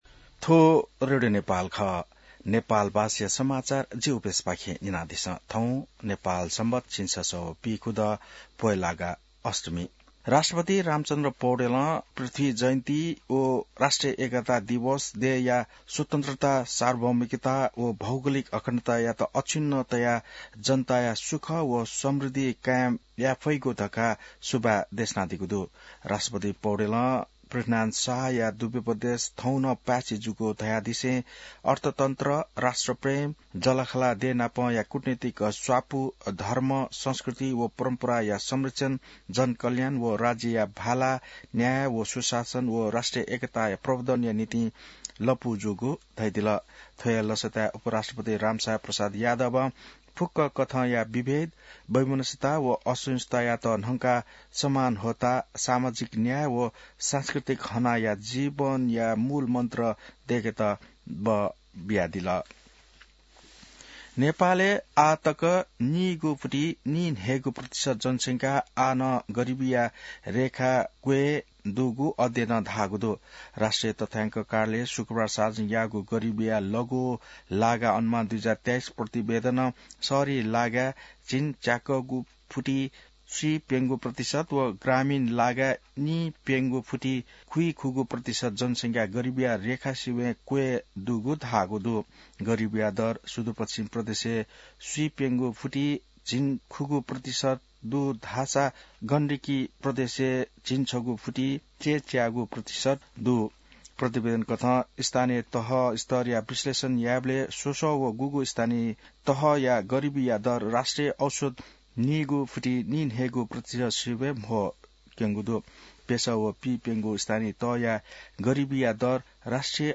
नेपाल भाषामा समाचार : २७ पुष , २०८२